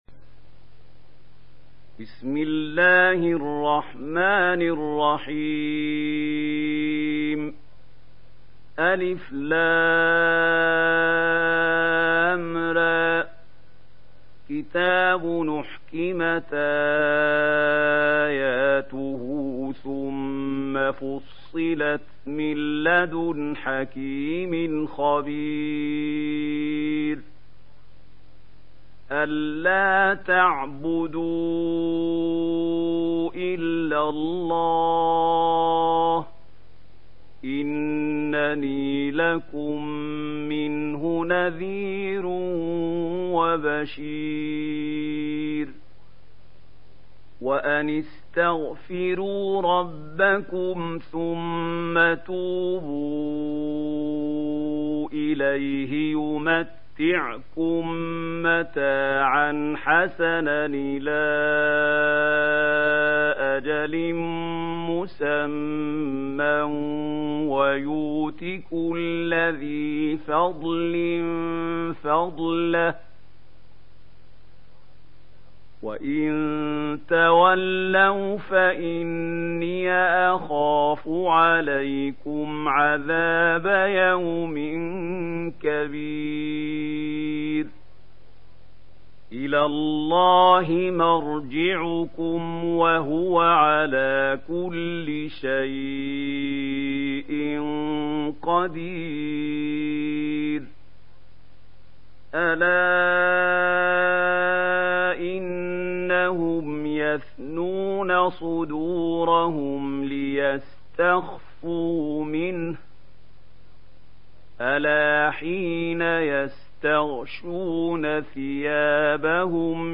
Surah Hud mp3 Download Mahmoud Khalil Al Hussary (Riwayat Warsh)